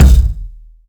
Kick (75).wav